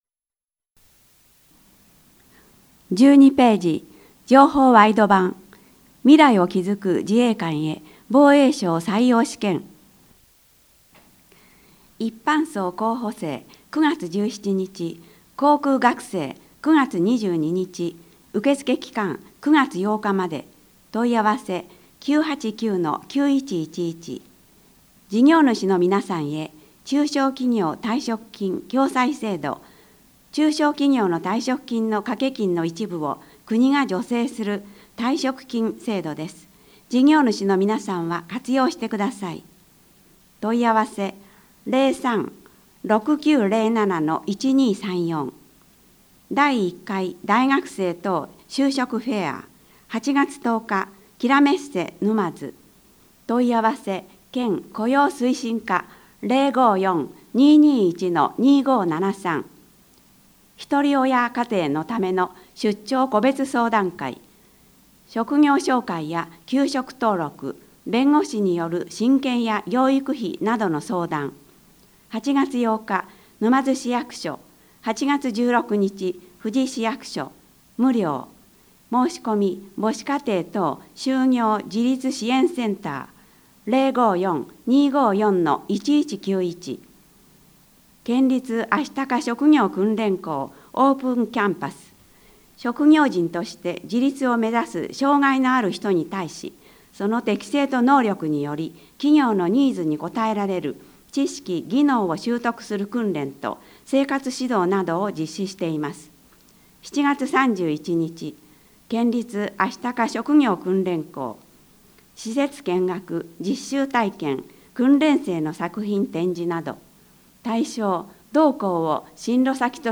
三島市社会福祉協議会で活動するボランティアグループ「やまなみ」は、視覚障害者への情報提供として「広報みしま」毎月１日号・１５日号を録音し、声の広報として送り届ける活動をしています。